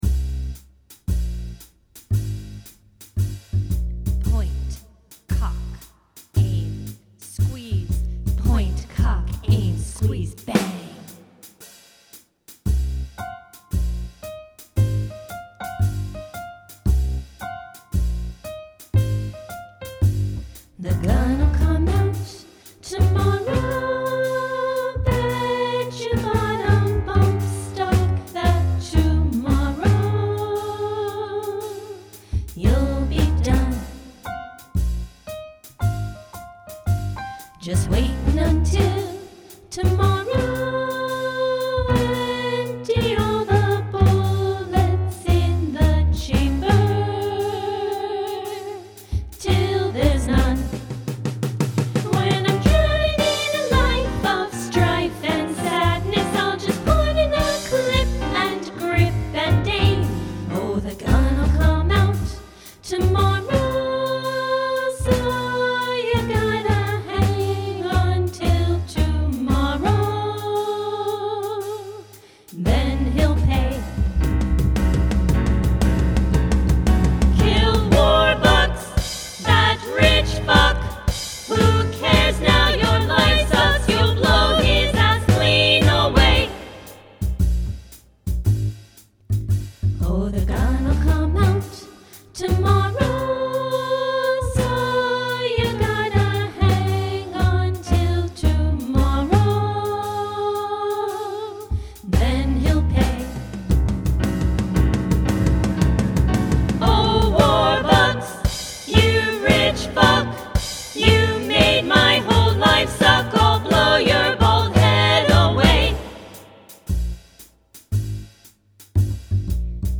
Song Demos
(Music; Demo Song Production and Accompaniment)